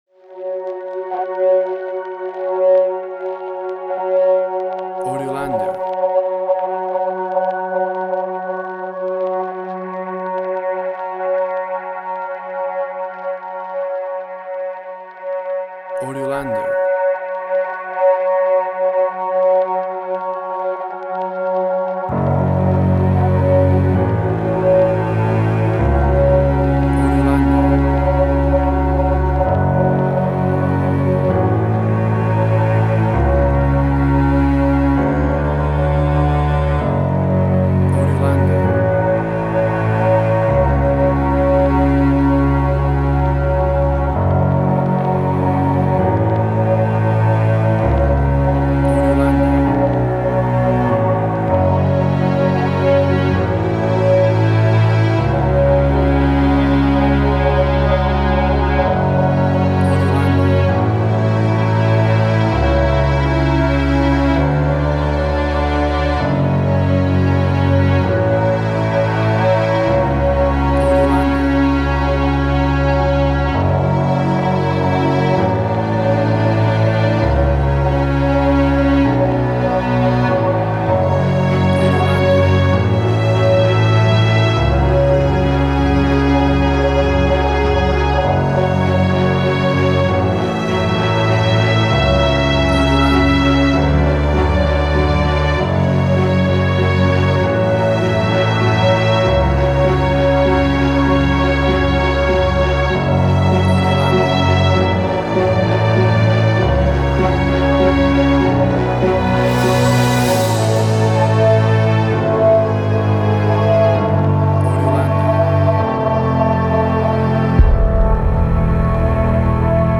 Post-Electronic.
emotional music
Tempo (BPM): 97